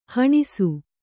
♪ haṇisu